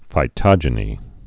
(fī-tŏjə-nē)